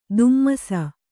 ♪ dmmasa